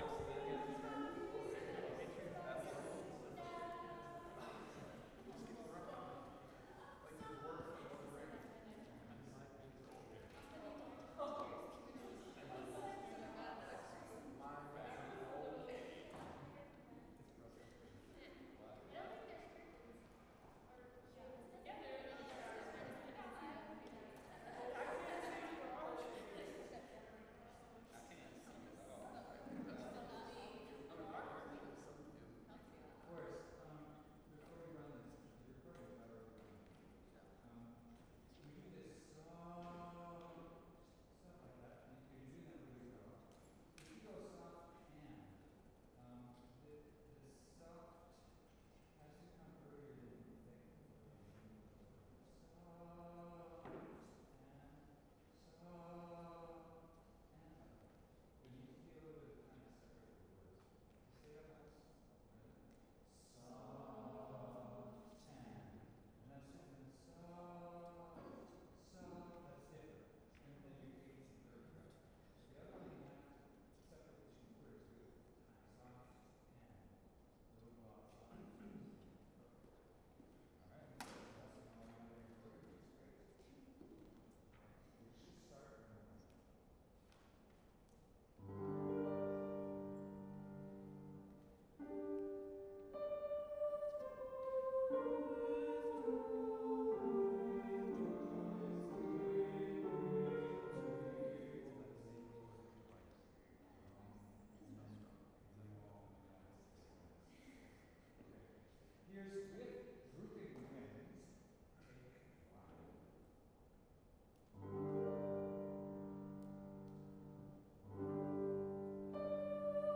With Drooping Wings rehearsal 9-23.WAV .wav 55MB Download Generate QR code